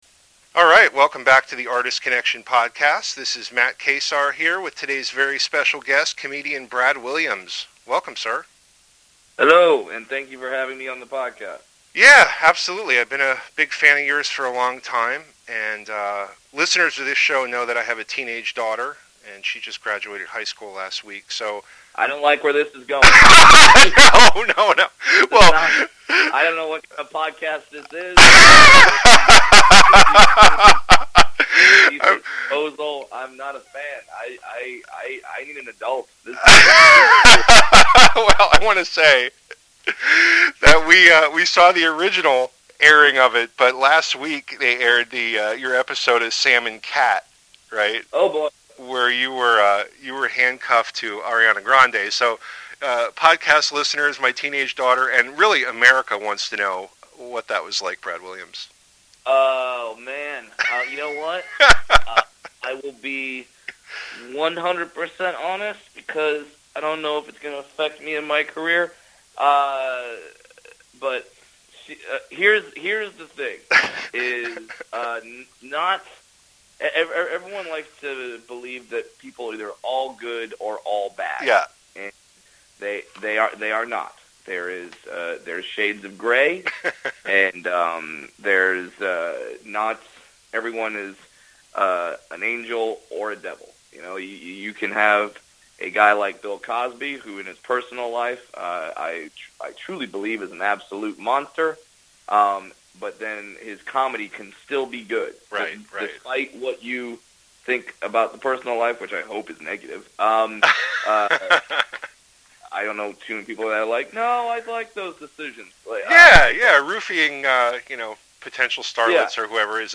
After that you will hear my interview with comedian Brad Williams.